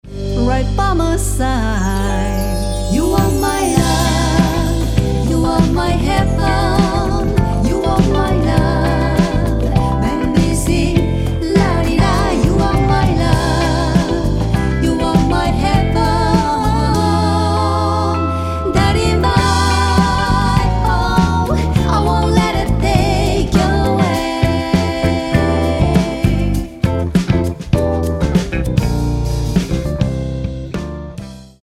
円熟のヴォーカルが描く、ソウル・ジャズ。
Vocal/Chorus
Drums